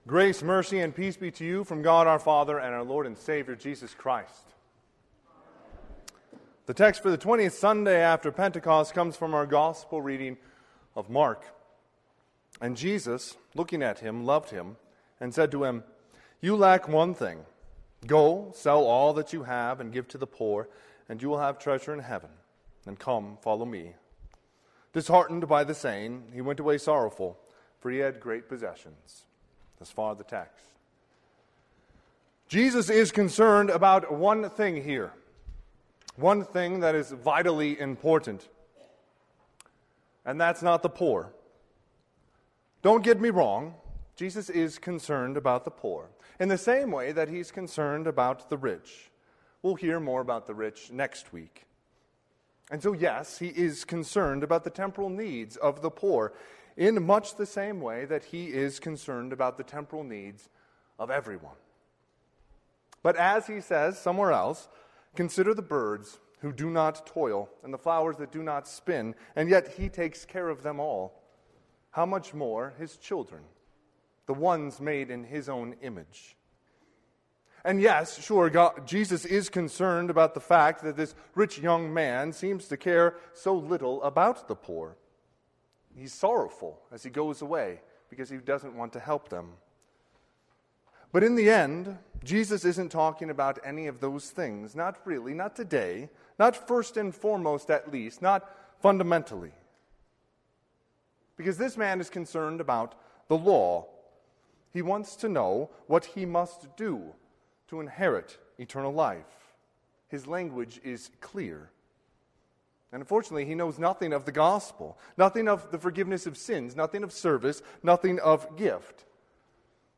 Sermon - 10/10/2021 - Wheat Ridge Lutheran Church, Wheat Ridge, Colorado
Twentieth Sunday after Pentecost